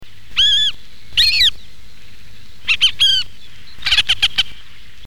Busard Saint-Martin
Circus cyaneus
busard_sm.mp3